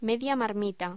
Locución: Media marmita